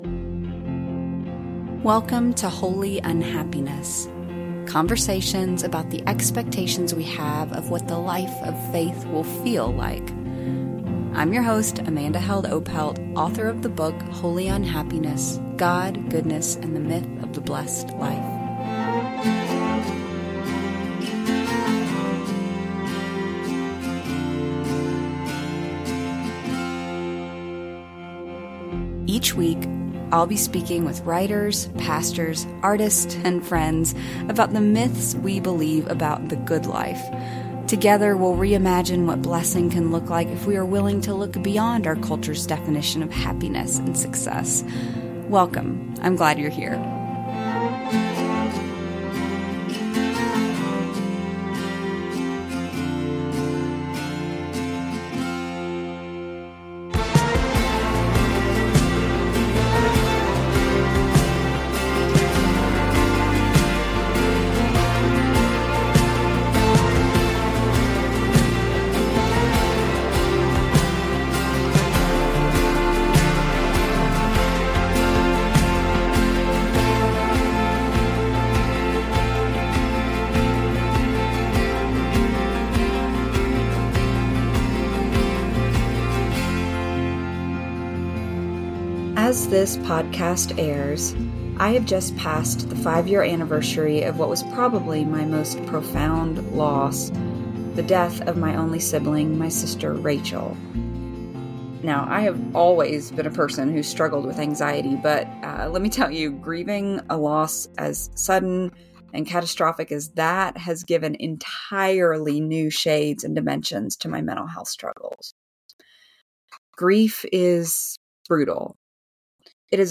Conversations about the expectations we have of what the life of faith will feel like.